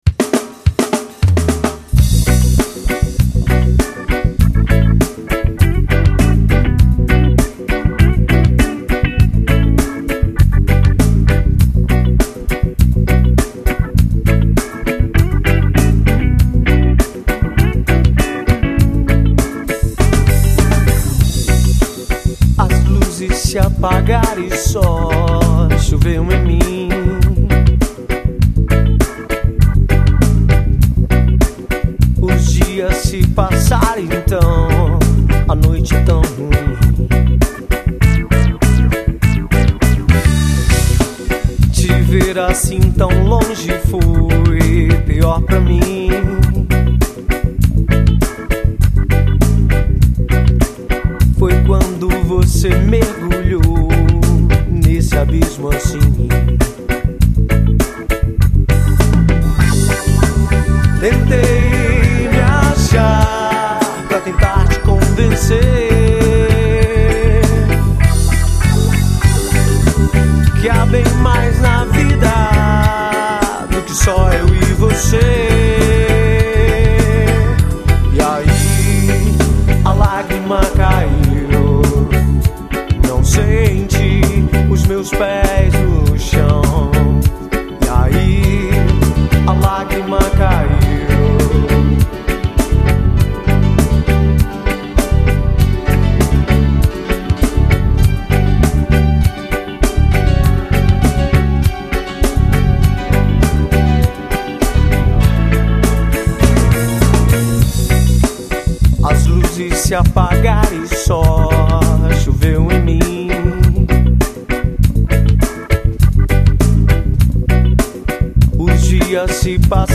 1973   03:49:00   Faixa:     Reggae